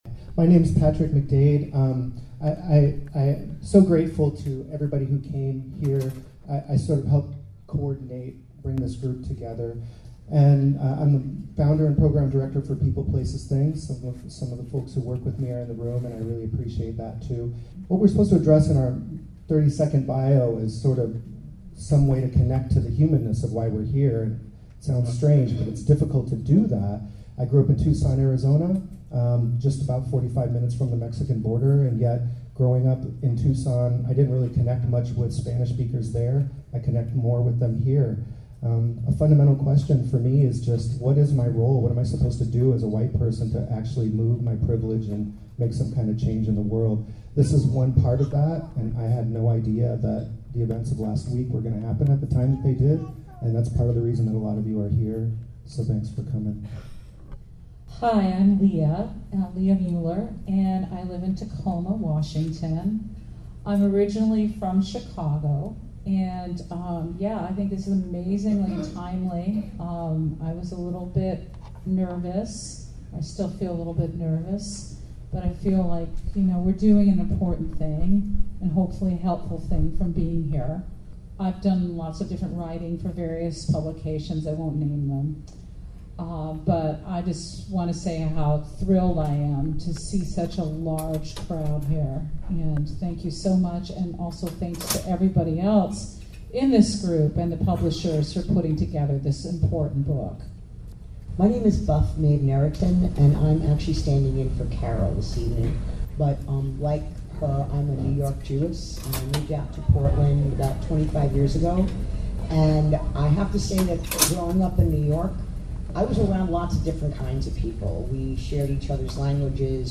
Breaking the White Code of Silence: A Collection of Personal Narratives Authors read condensed compilations of their essays from the book. This program was recorded on Tuesday July 12th at McMenamins Kennedy School. Race Talks is a Conversation Series Filling the spaces between race with compassion and education.